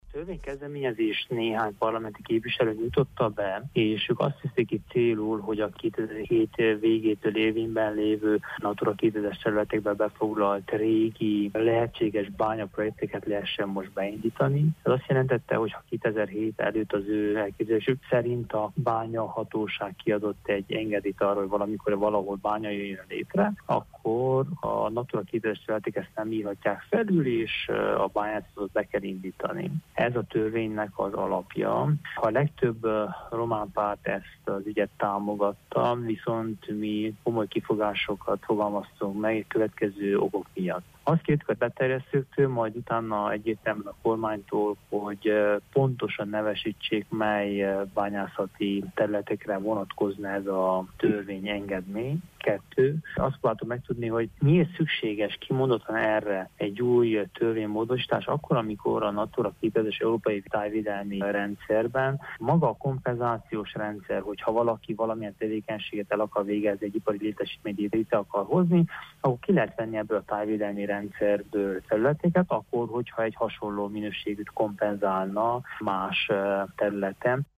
A Képviselőház tegnap döntő házként úgy határozott, hogy a védett területek határait módosítani lehet azon területek védettségének feloldásával, amelyek tavaly júniusban bányászati tevékenységre vonatkozó engedélyekkel rendelkeztek. Utóbbi jogszabályt az RMDSZ képviselői nem szavazták meg. Korodi Attila képviselőházi frakcióvezetőt hallják: